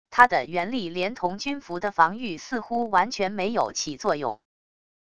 他的原力连同军服的防御似乎完全没有起作用wav音频生成系统WAV Audio Player